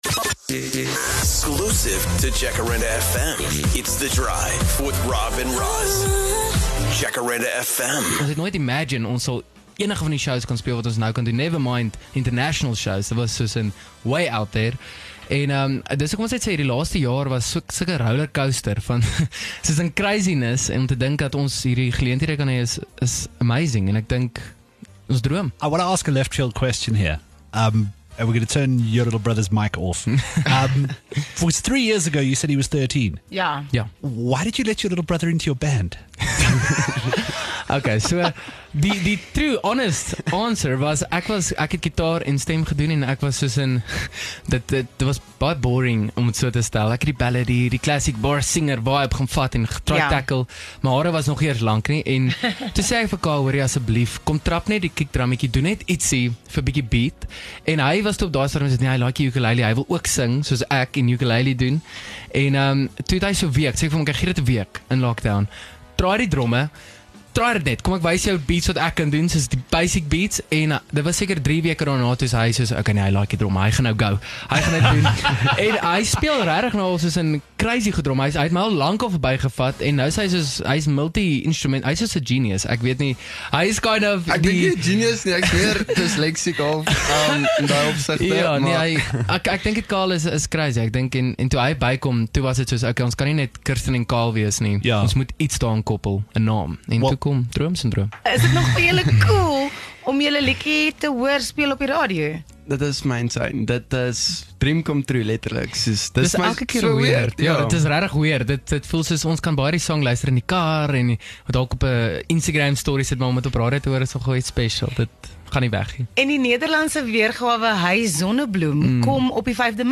Droomsindroom is gereed om Afrikaans en Nederlandse musiek luisteraars, se harte te steel met nuwe opwindende musiek. Die broers maak reg vir hulle eerste internasionale toer en het kon kuier in studio om vir ons alles te vertel!